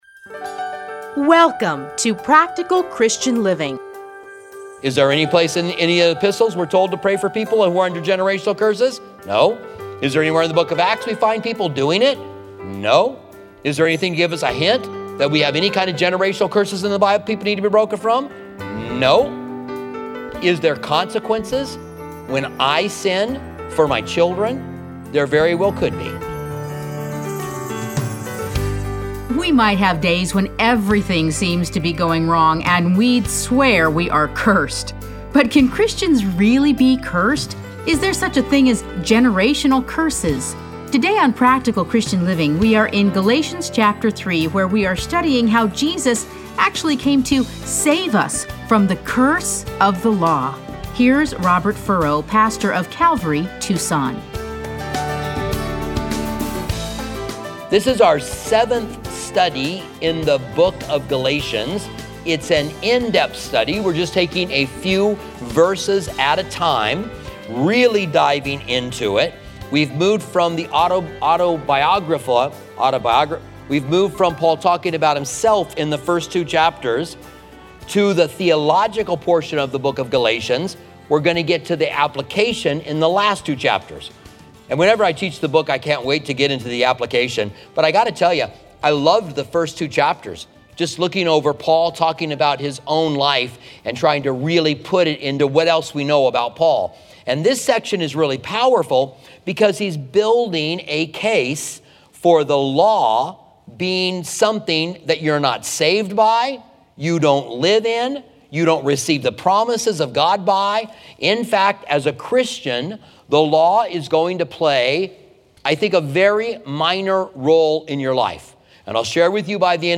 Listen to a teaching from Galatians 3:10-14.